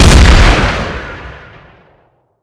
sol_reklam_link sag_reklam_link Warrock Oyun Dosyalar� Ana Sayfa > Sound > Weapons > Rpg7 Dosya Ad� Boyutu Son D�zenleme ..
WR_fire.wav